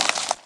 PixelPerfectionCE/assets/minecraft/sounds/mob/spider/say2.ogg at 6d6f48947e7ae03e402980b2510fdc3b2fb8634b